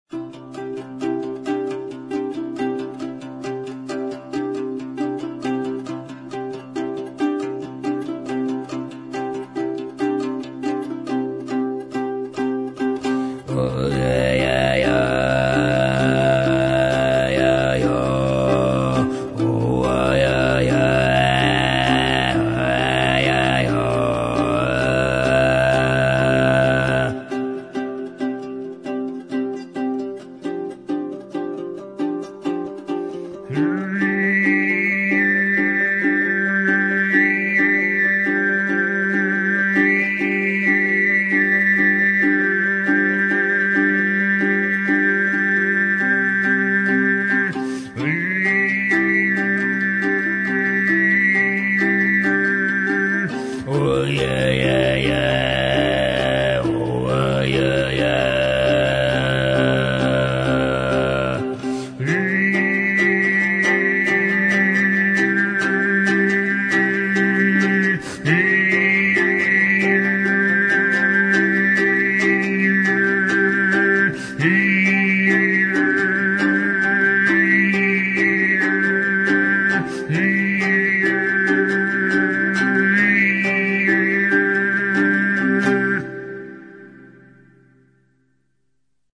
Инструментальные пьесы [9]
Сыгыт звучит в самом верхнем регистре, где он близок свисту.
горловое пение, Жетыген